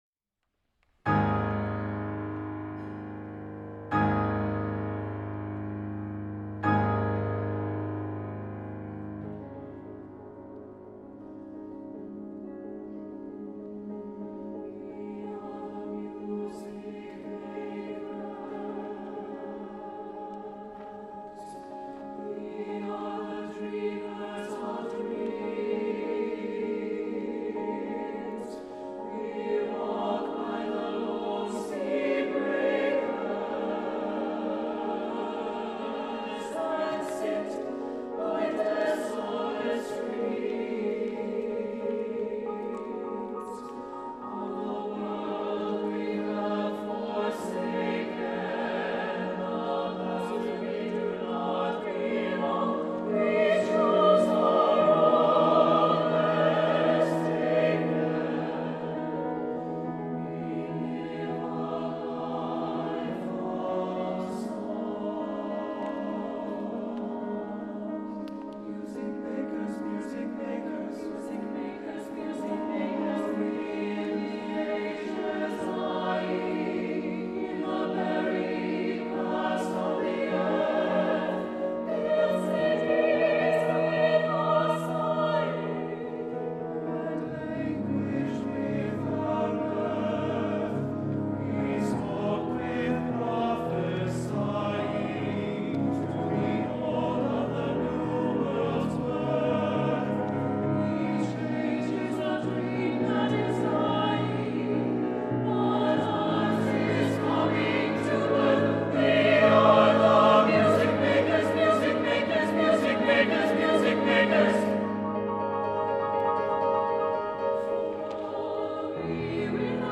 for SATB Chorus and Piano (2003)